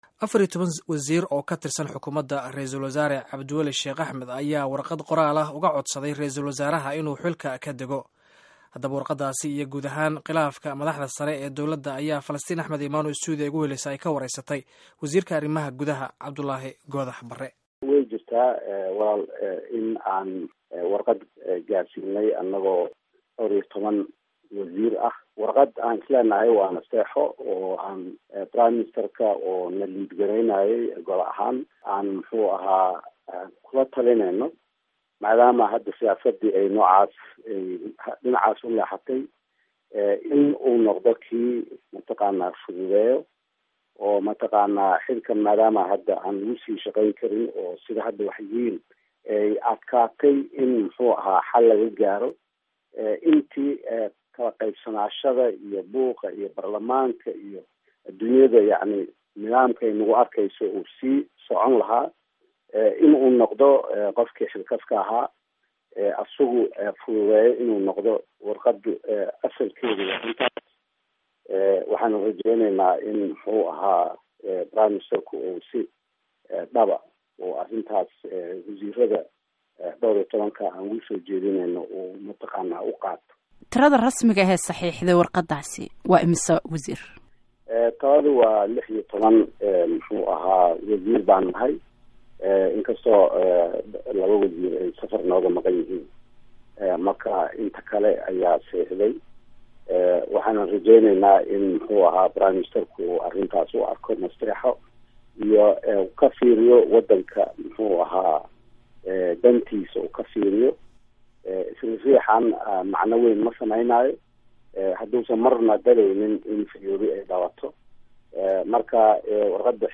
Dhegayso: Waraysi Wasiirka Arrimaha Gudaha